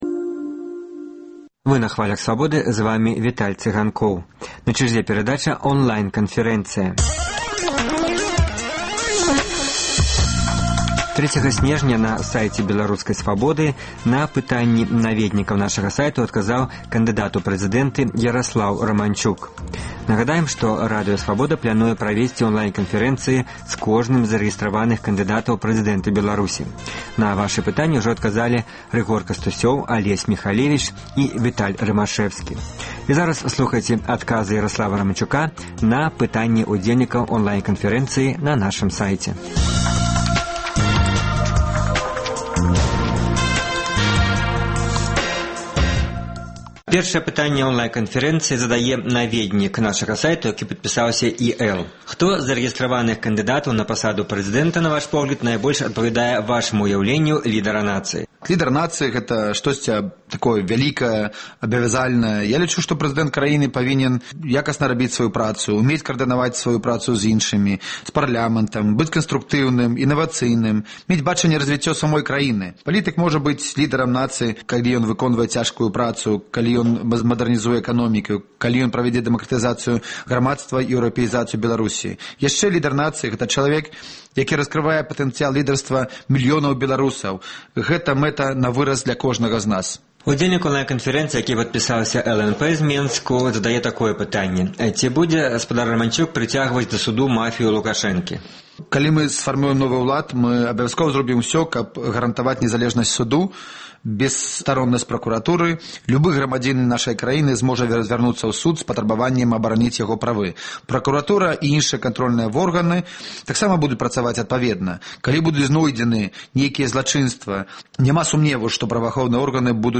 На пытаньні наведнікаў нашага сайту адказаў кандыдат у прэзыдэнты Яраслаў Раманчук.